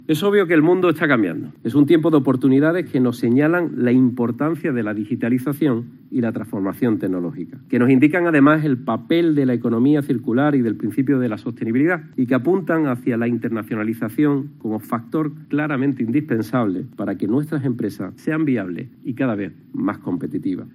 Escucha al presidente de la Junta de Andalucía, Juanma Moreno